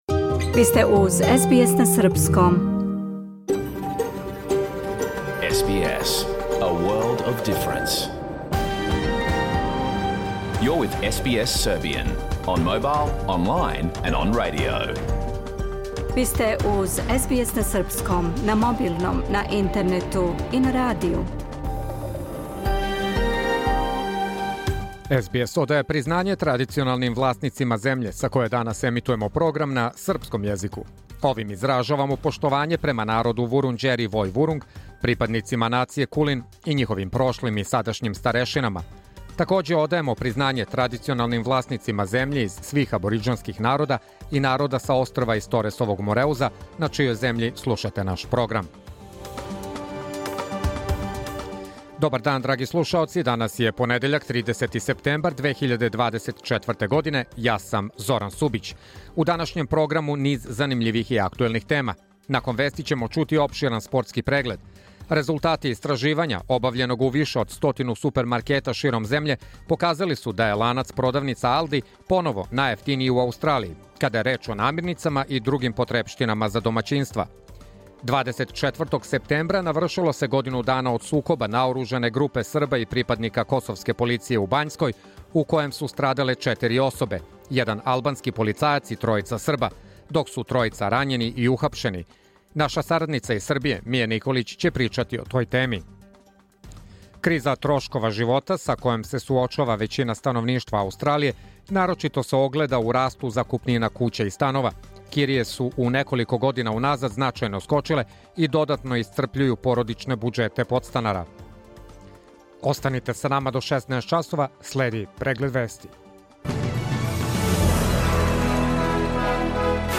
Програм емитован уживо 30. септембра 2024. године
Уколико сте пропустили данашњу емисију, можете је послушати у целини као подкаст, без реклама.